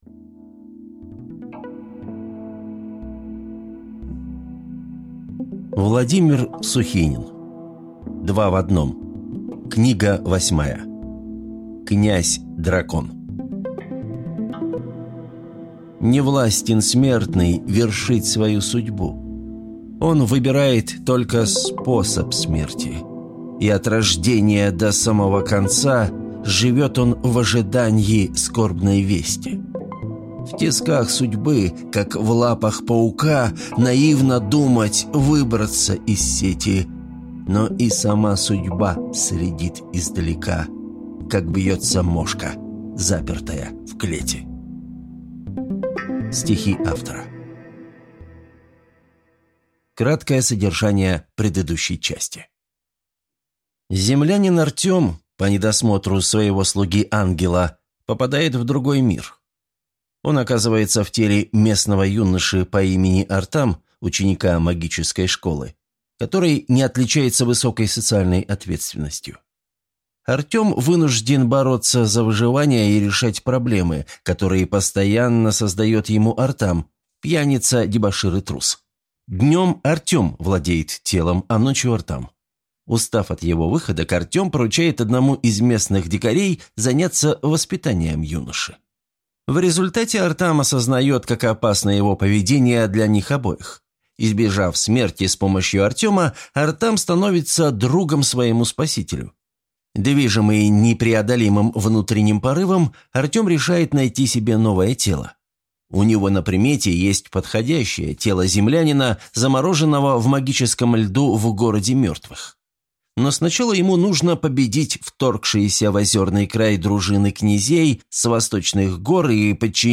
Аудиокнига «Два в одном. Князь – дракон».